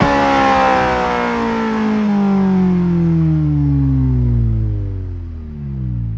rr3-assets/files/.depot/audio/Vehicles/i4_01/i4_01_decel.wav
i4_01_decel.wav